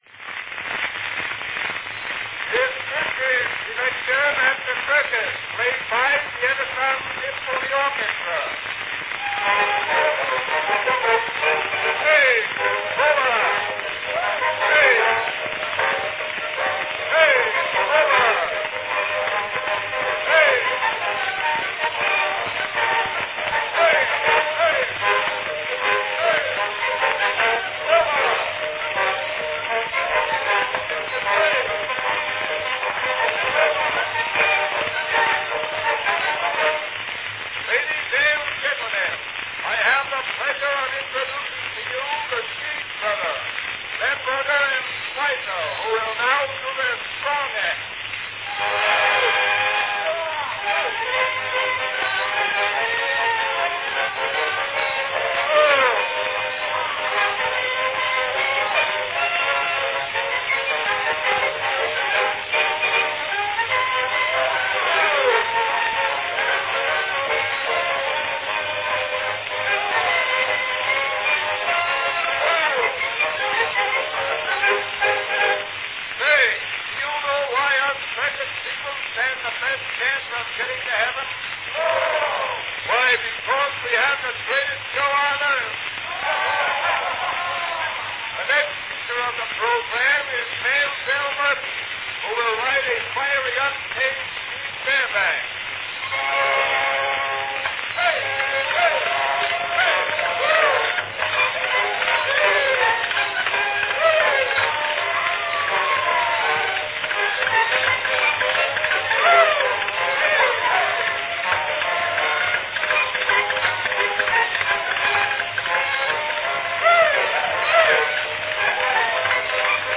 From 1899, good humor and big fun under the big top
Category Descriptive selection